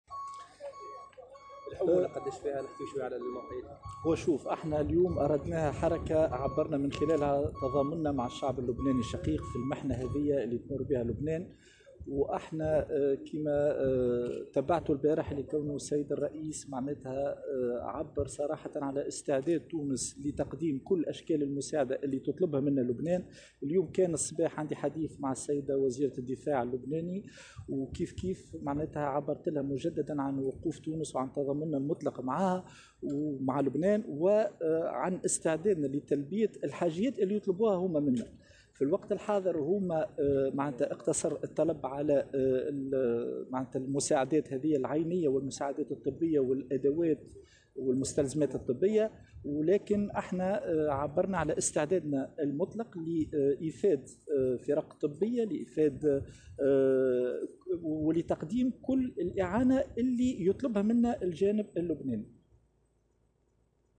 قال وزير الدفاع الوطني عماد الحزقي في تصرح لـ "الجوهرة أف أم" صباح اليوم إنه أجرى اتصالا هاتفيا مع نظيرته اللبنانية، أكد من خلاله استعداد تونس لمساعدة لبنان وتلبية الحاجيات التي يعبر عنها.